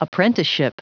Prononciation du mot apprenticeship en anglais (fichier audio)
Prononciation du mot : apprenticeship